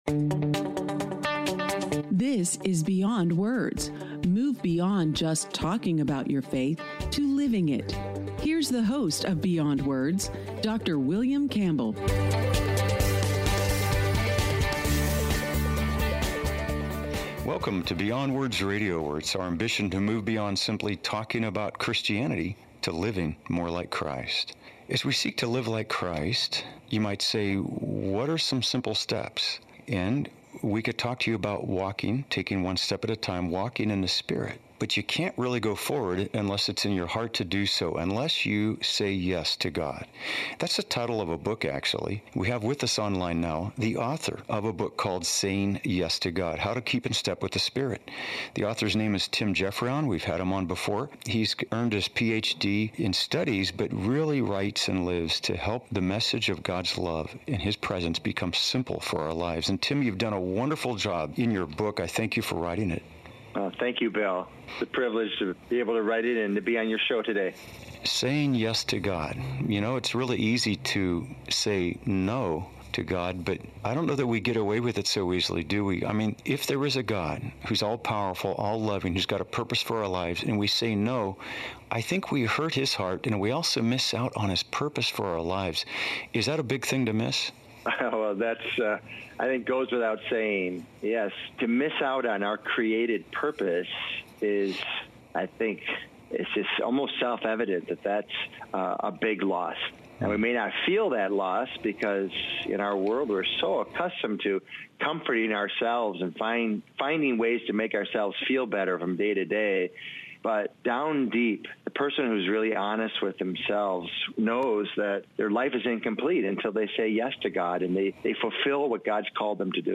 Radio Interview on KTIS